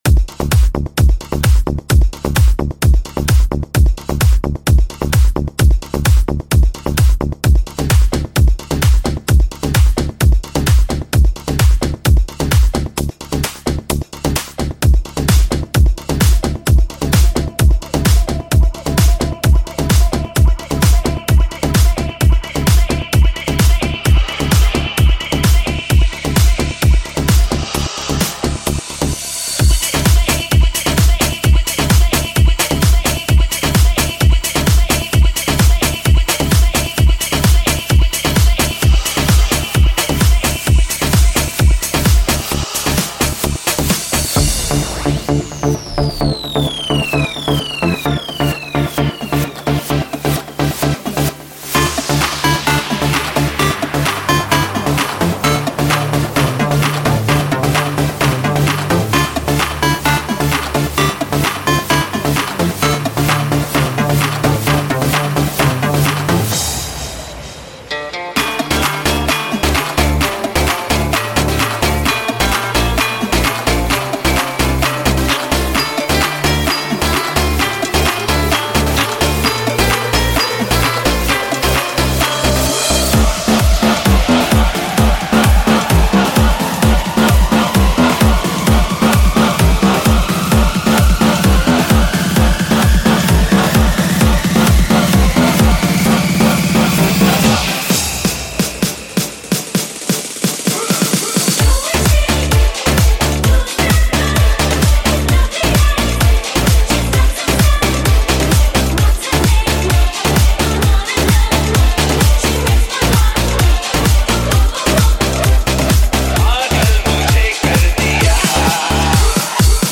Singer : Old Hindi DJ Remix